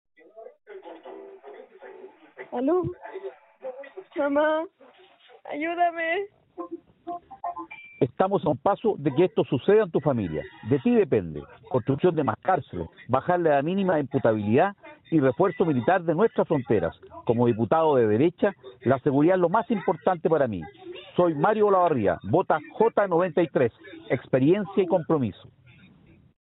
“¡Ayúdame!”: pide una voz de una menor en el polémico mensaje usado por el ex alcalde de Colina.